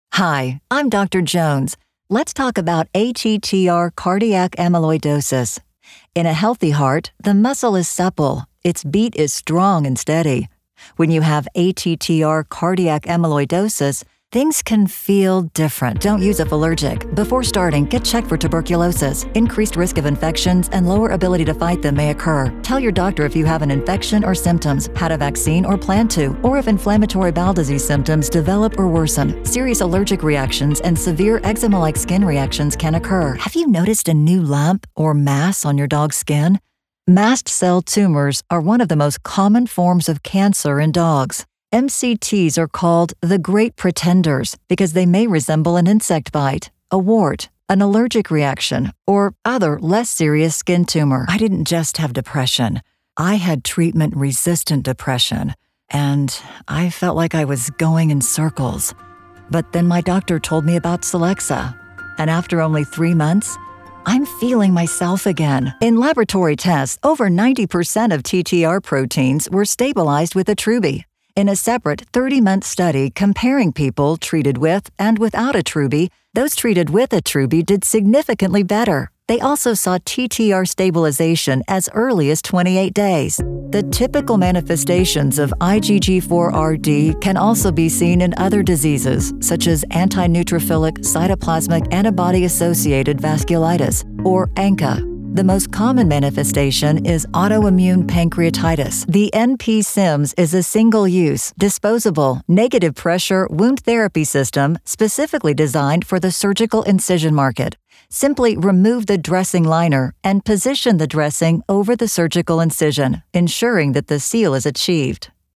Warm, Confident, Elegant.
Medical